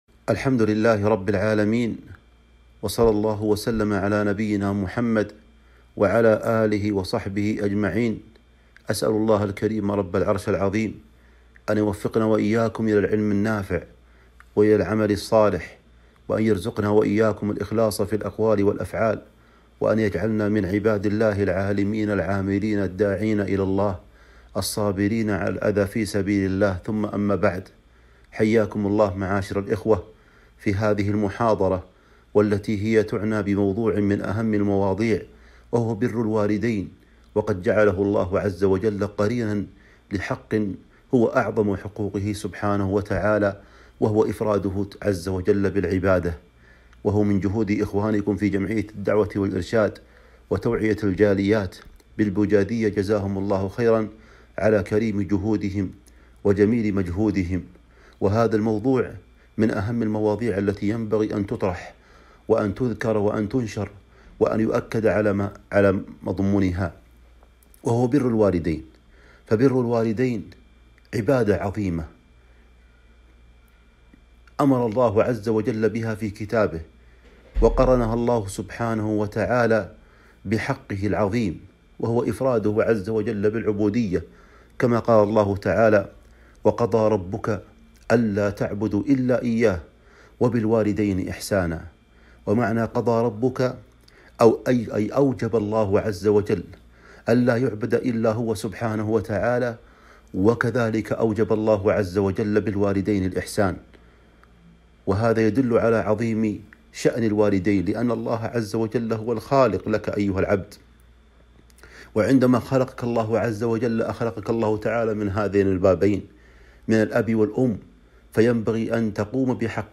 كلمة - الحث على بر الوالدين وصلة الارحام